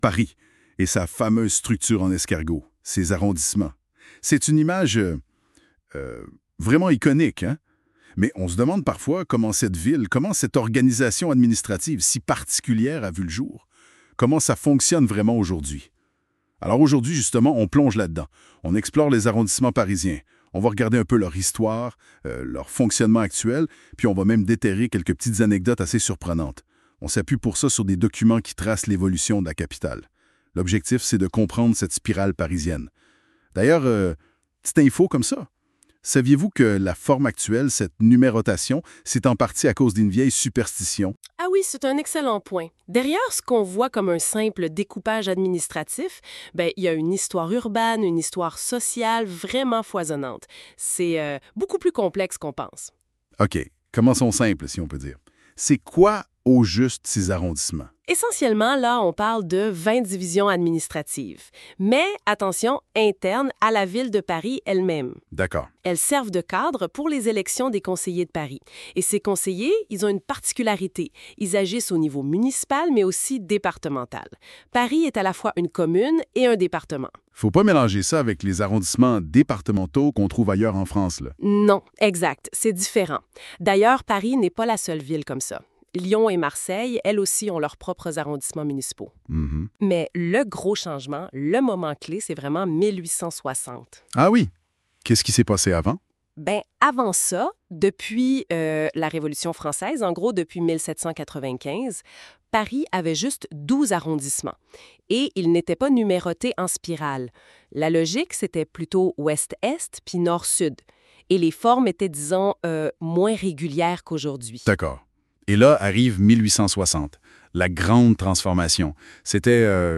Québécois